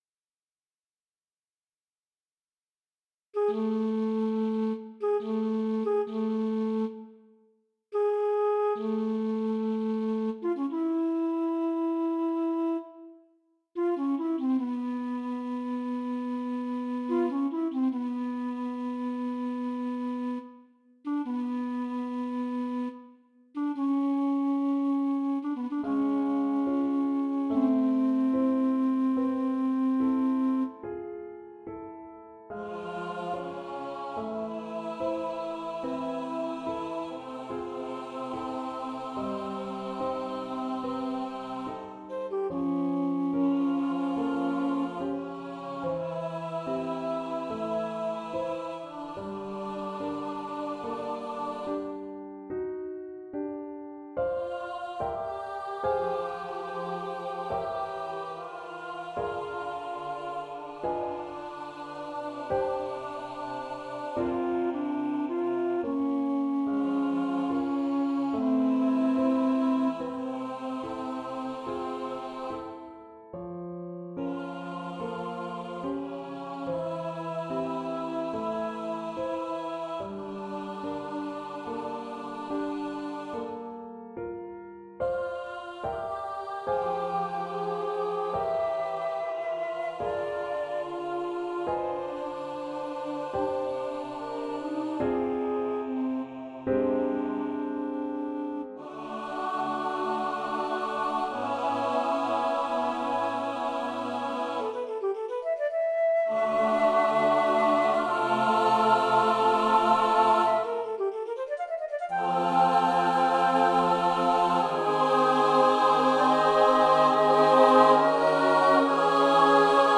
for SATB and Piano